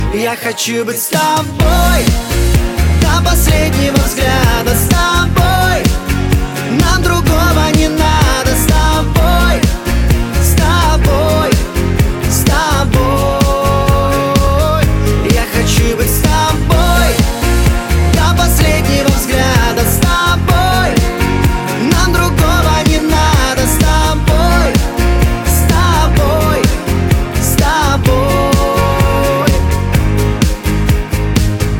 поп
мужской вокал